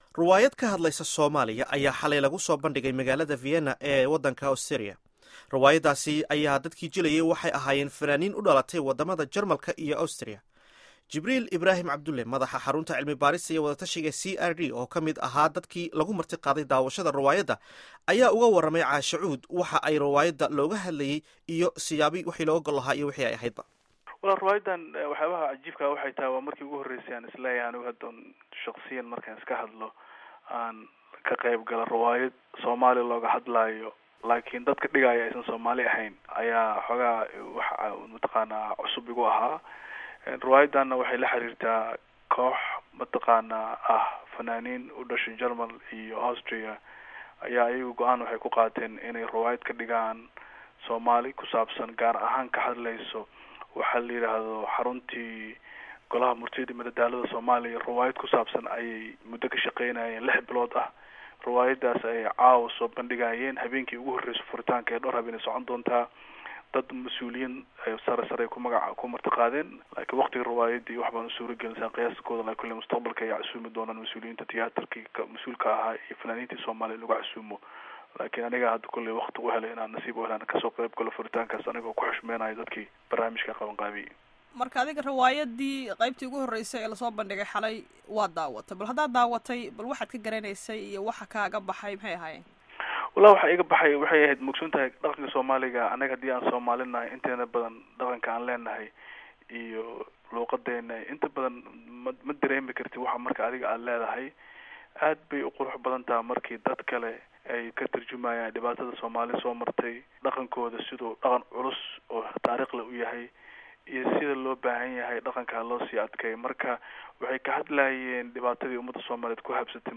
Wareysiga Riwaayadda